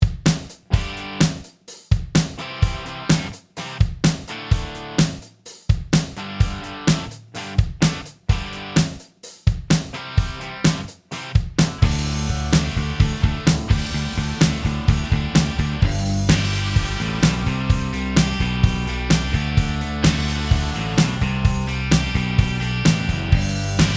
Minus Lead Guitar And Solo Rock 3:34 Buy £1.50